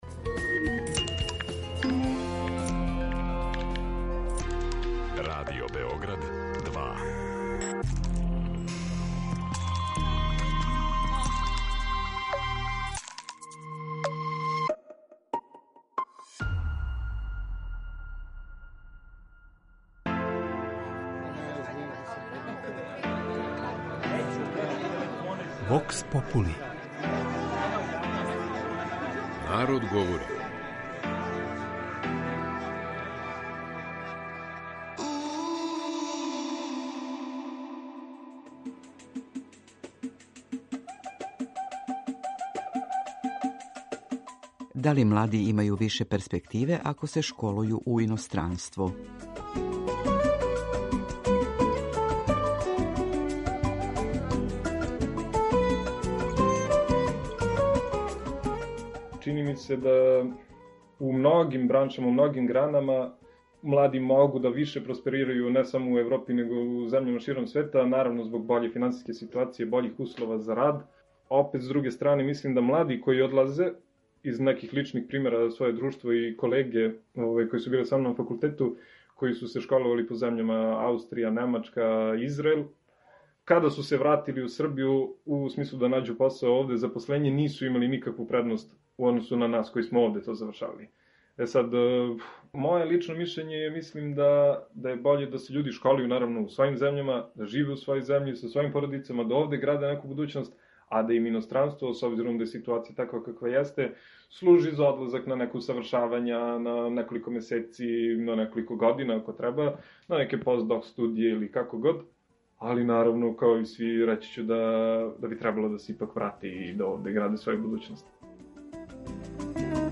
Вокс попули